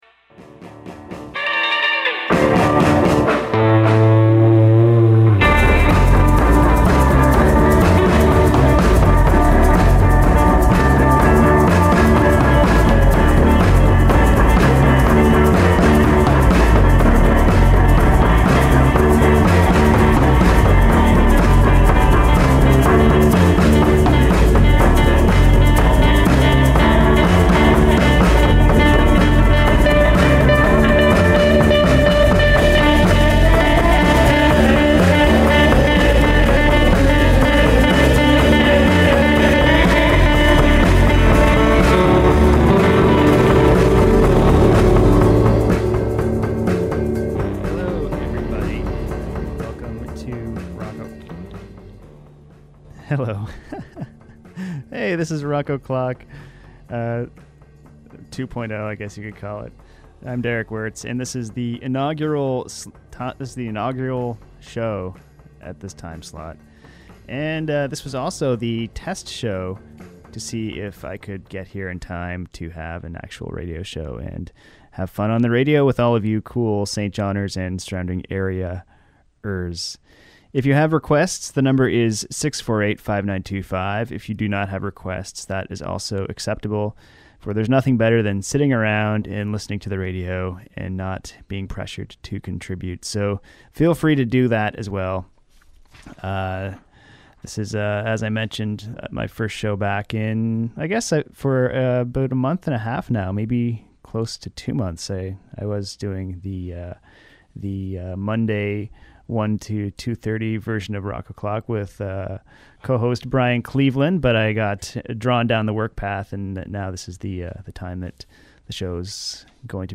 Weekly community radio program with a heavy new and old Canadian music focus